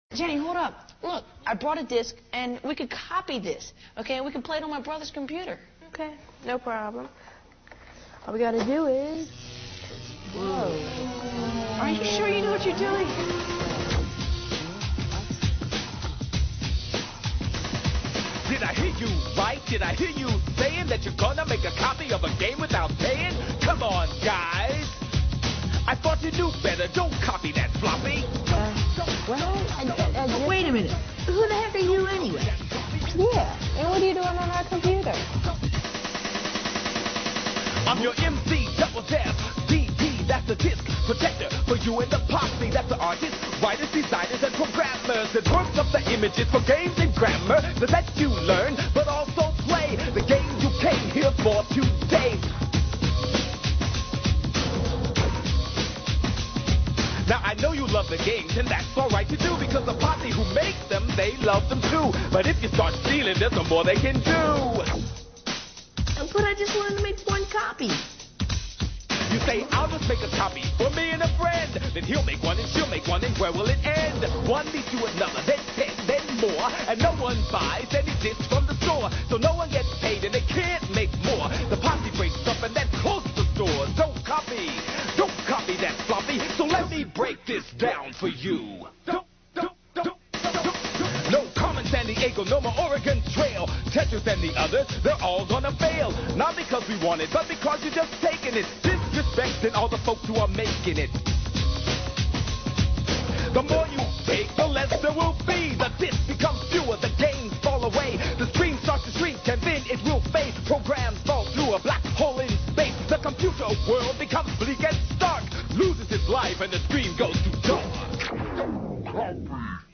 His method of lecture is a hip-hop style song and dance.
I ripped and edited the audio from the video.